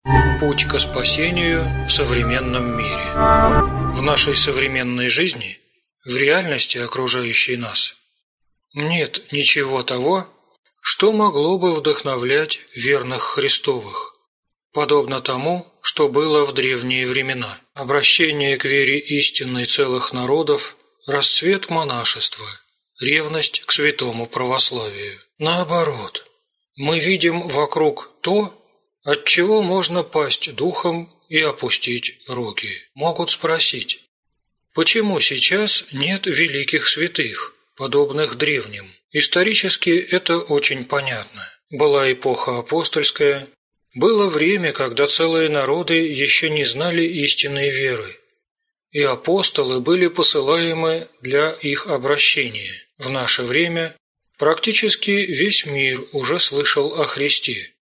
Аудиокнига Путь ко спасению в современном мире | Библиотека аудиокниг